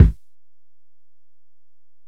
Kick (23).wav